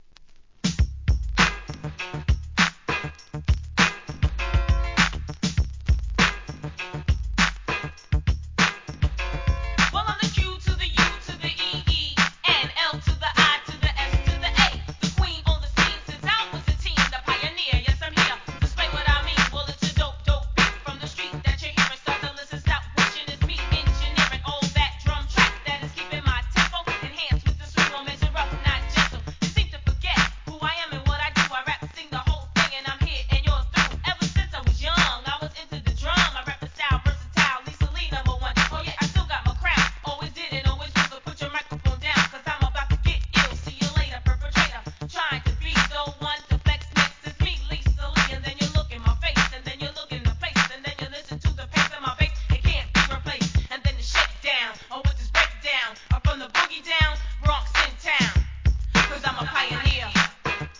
HIP HOP/R&B
OLD SCHOOLコンピレーション